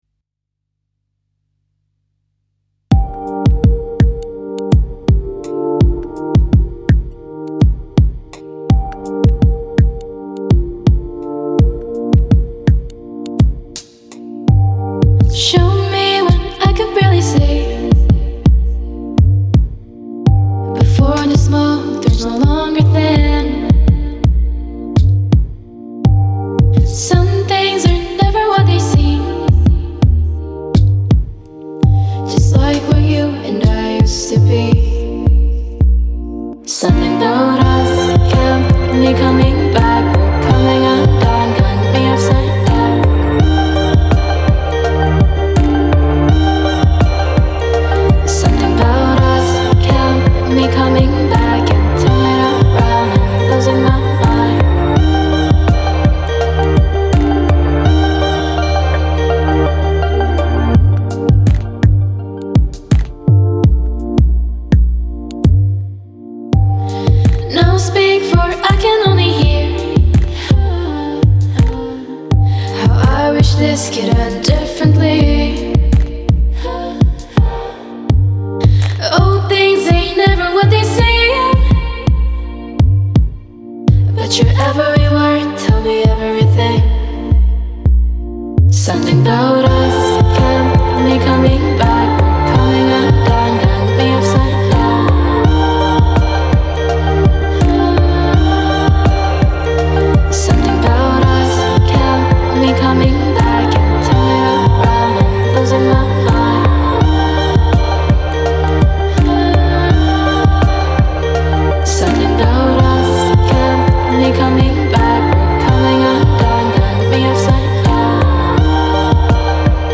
seorang soloist wanita dengan suara yang memikat
alunan musik yang bagaikan mimpi